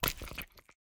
assets / minecraft / sounds / mob / cat / eat2.ogg
eat2.ogg